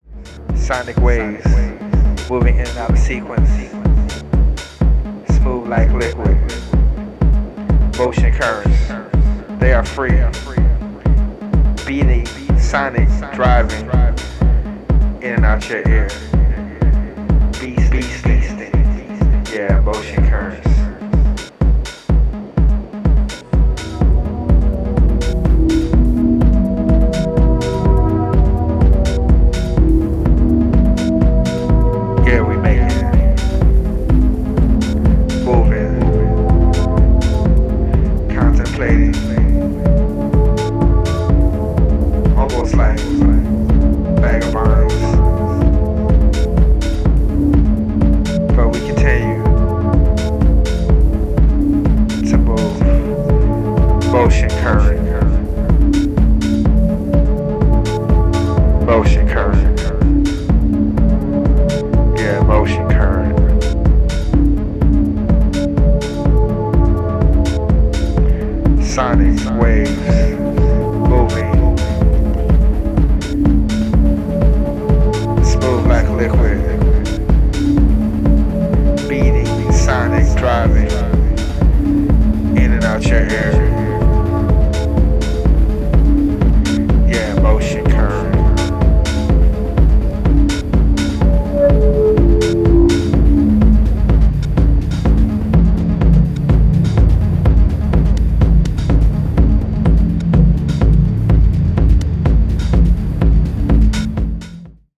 supplier of essential dance music
Techno